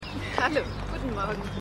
MissionErde_3x07_FrauHG.mp3